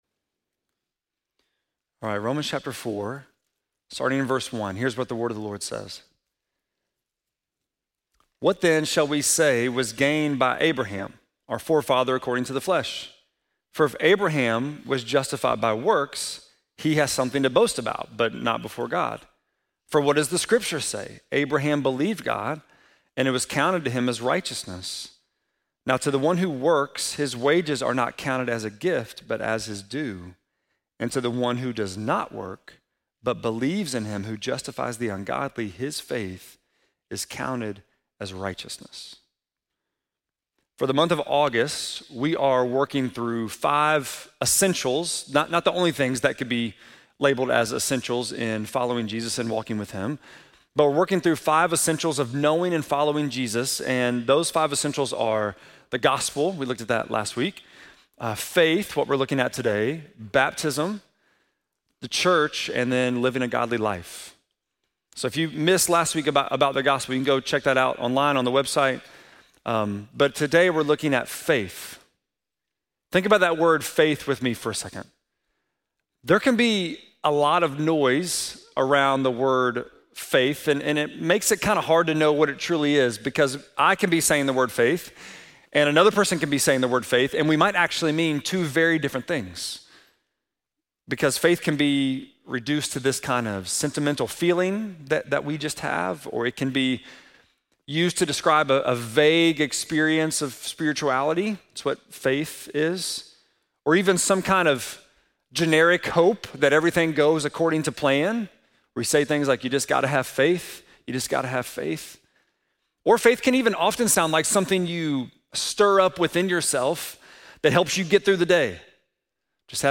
8.10-sermon.mp3